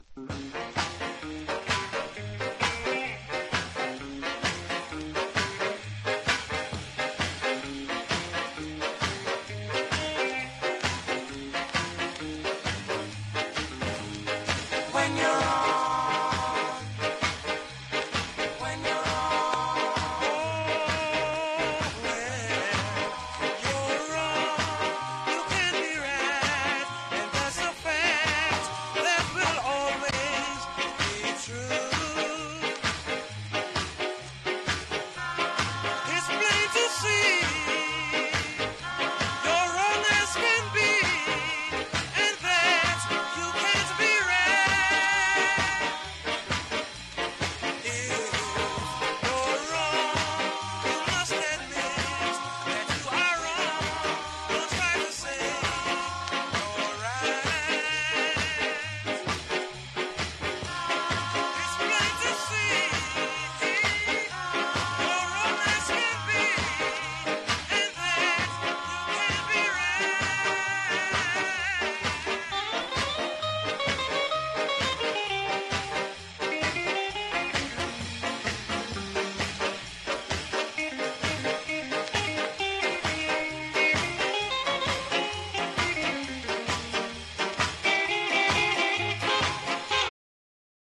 当時の空気を感じさせる演奏と絶妙なコーラスワークが◎。